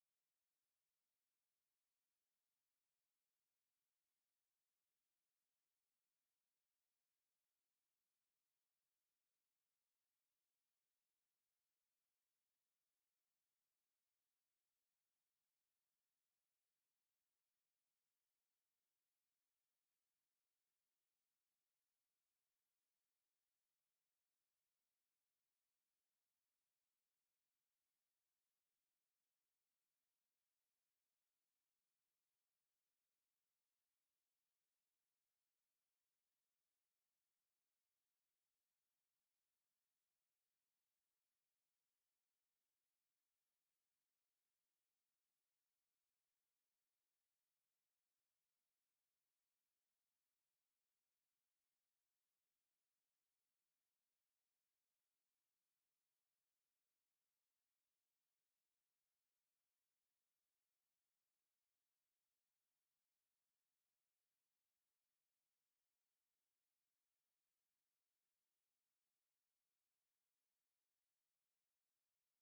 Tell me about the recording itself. Secret White House Tapes | Lyndon B. Johnson Presidency